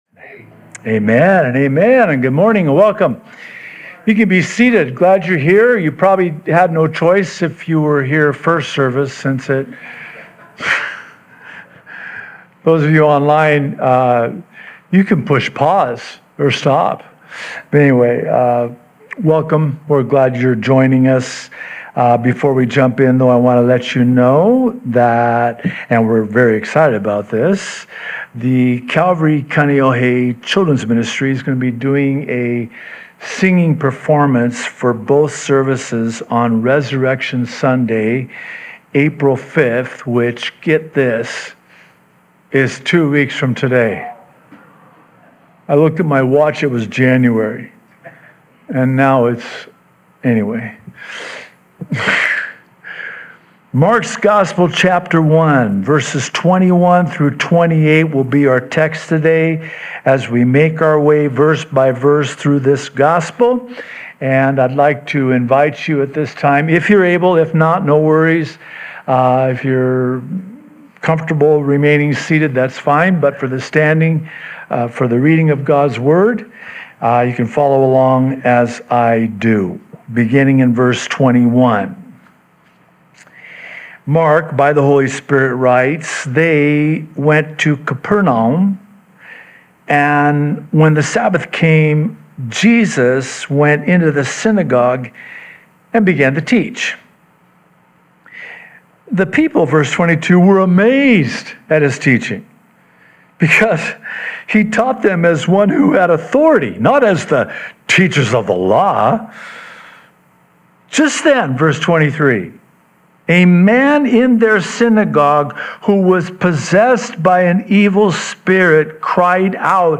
Sunday Sermon, What I Might Be Missing In My Life Is Just Jesus – Mark 1:21-28 – Sunday, March 22nd, 2026